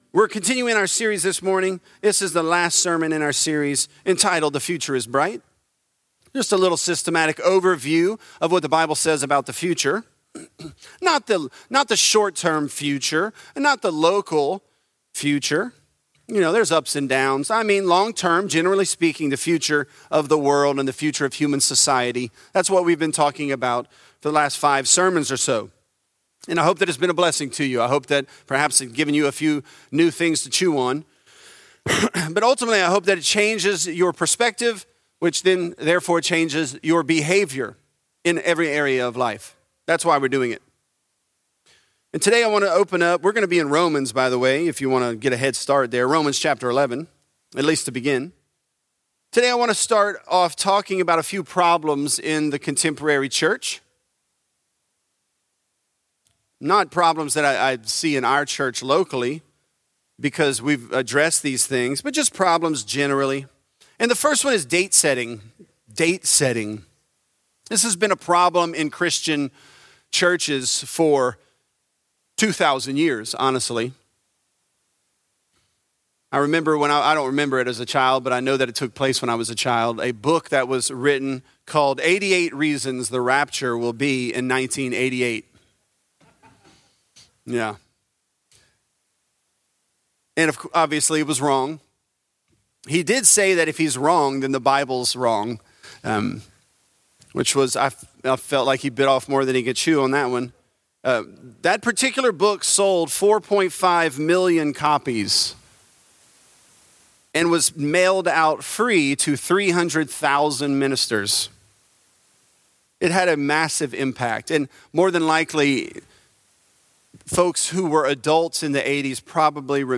The Future Is Bright: Riches For The World | Lafayette - Sermon (Romans 11)